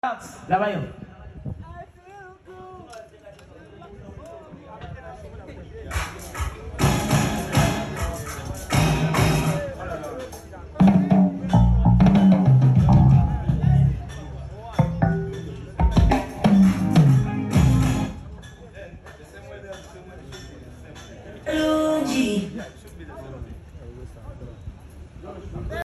all white concert sound check